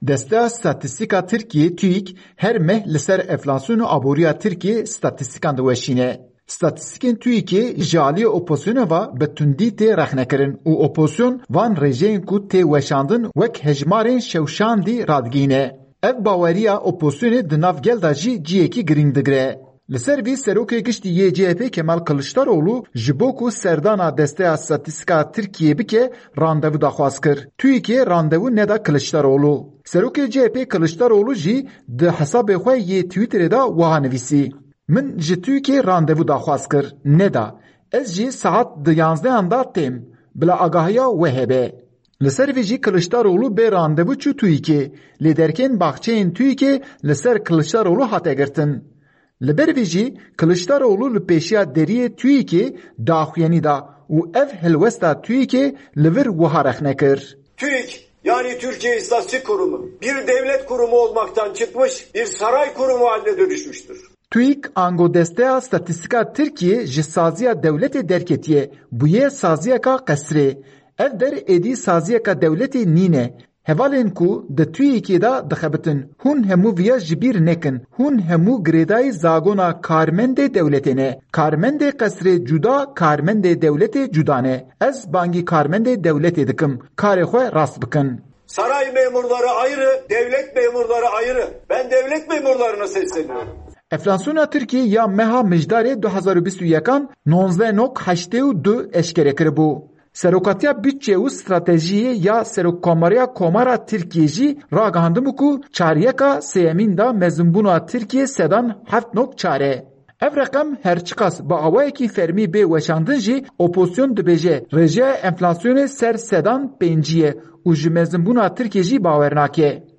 Li ber vî jî Kiliçdaroglu li pêşîya derîyê TUÎK’ê daxûyanî da û ev helwesta TUÎK li vir wiha rexne kir: